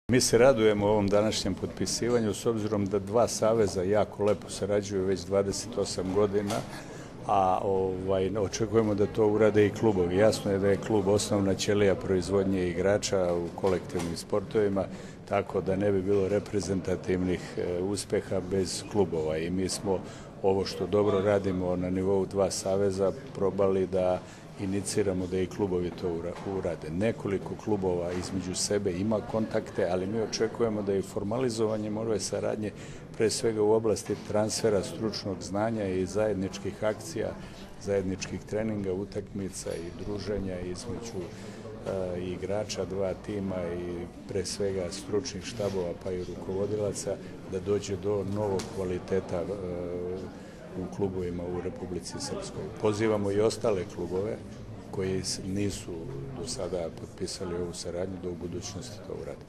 U organizaciji Odbojkaškog saveza Srbije i Odbojkaškog saveza Republike Srpske danas je u Bijeljini priređeno svečano potpisivanje Protokola o saradnji između klubova iz Srbije i Republike Srpske.
Izjava